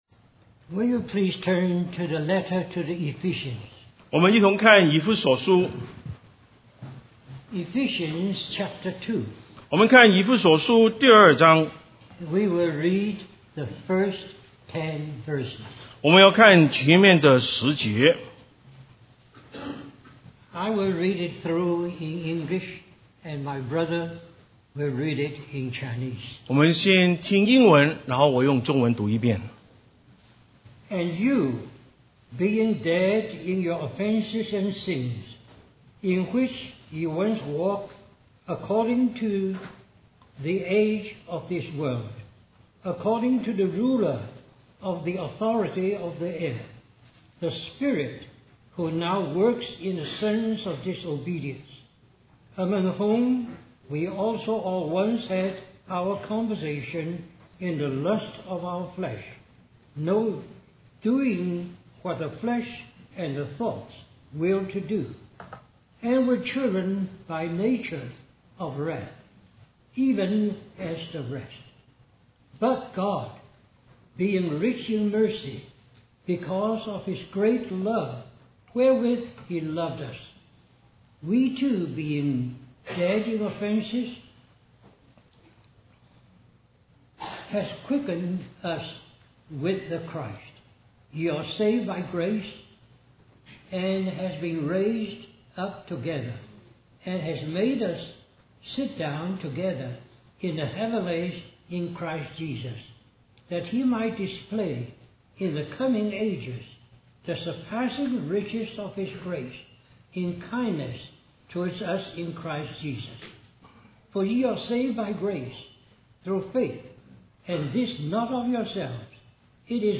A collection of Christ focused messages published by the Christian Testimony Ministry in Richmond, VA.
Vancouver, British Columbia, CA